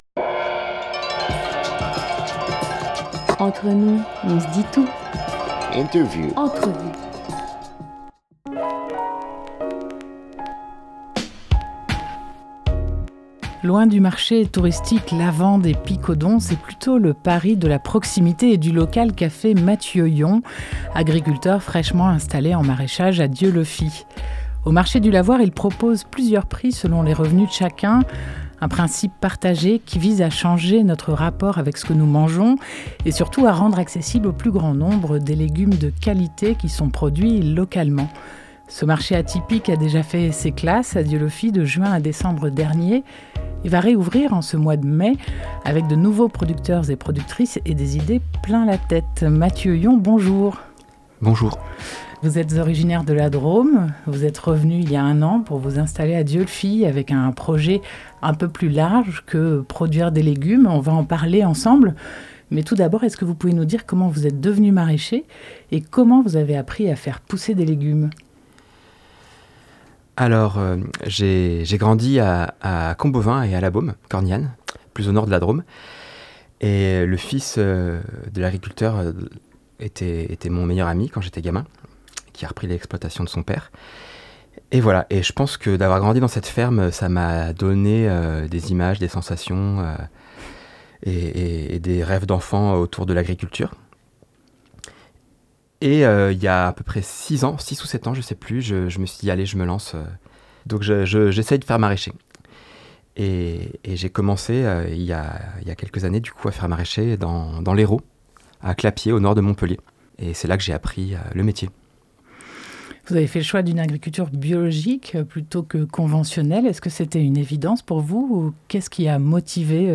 2 mai 2022 7:00 | Interview, manger